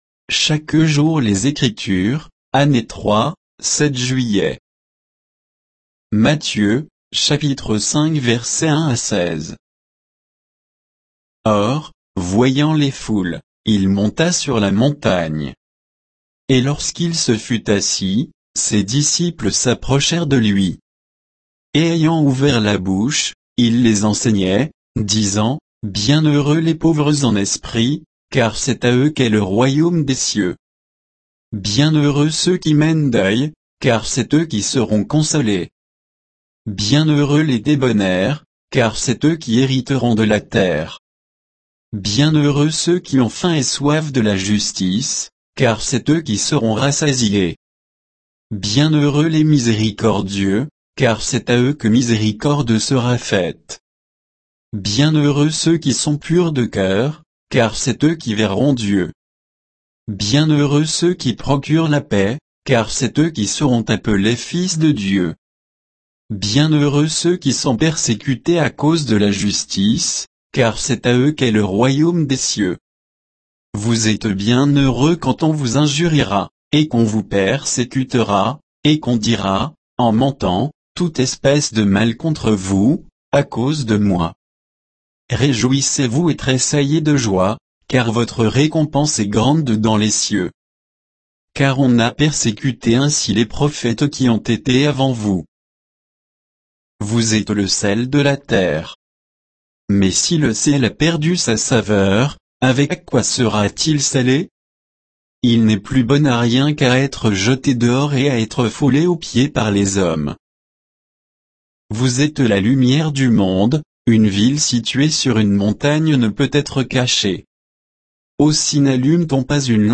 Méditation quoditienne de Chaque jour les Écritures sur Matthieu 5